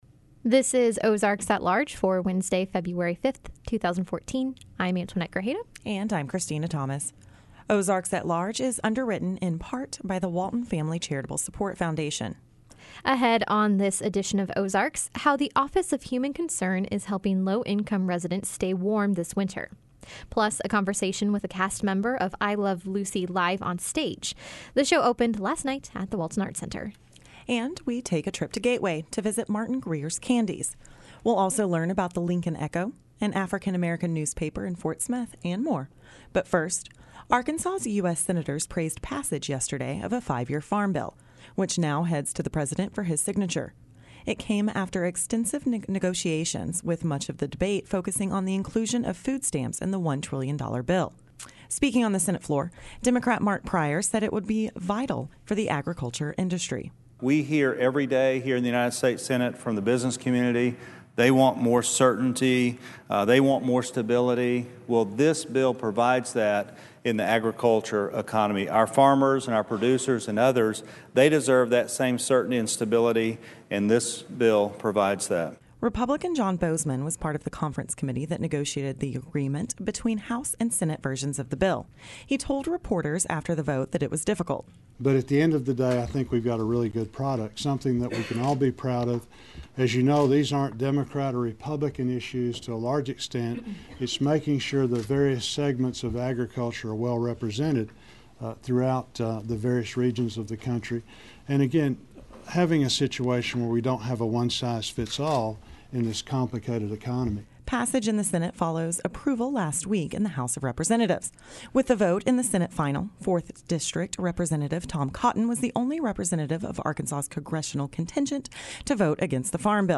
Ahead on this edition of Ozarks, how the Office of Human Concern is helping low-income residents stay warm this winter. Plus, a conversation with a cast member of I Love Lucy Live on Stage.